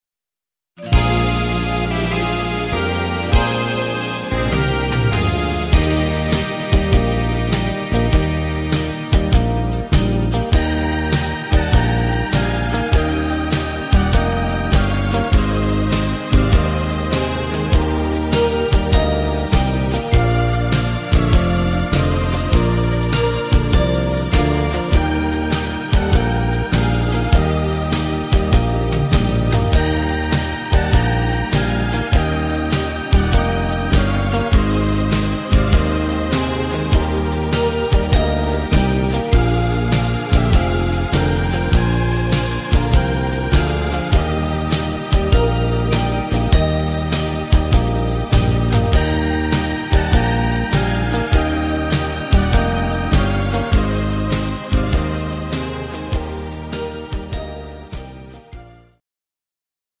instr. Saxohon